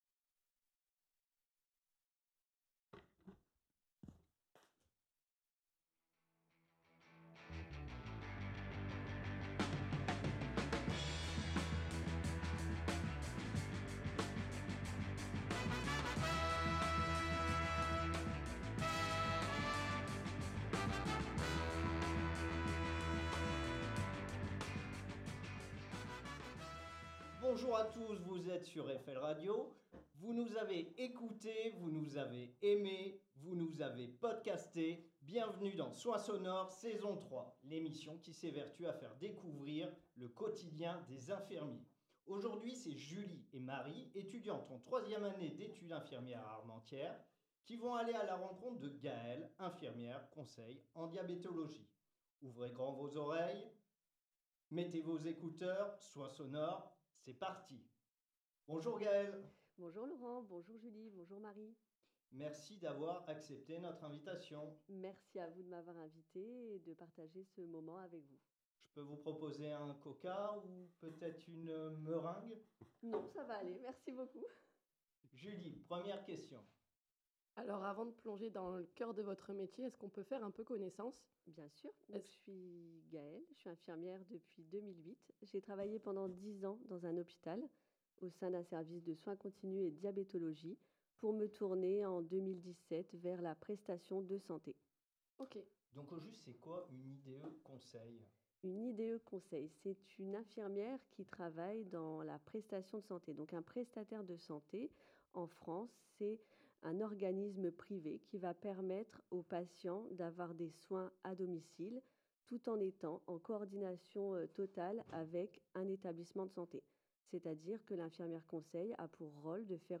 Pour cette seconde saison de Soins sonores, toujours en collaboration avec L'IFSI d'Armentières FL Radio vous propose des rencontres entre des infirmières ou infirmiers professionnels et des étudiants de 3ème année qui vont embrasser ce métier de soignant.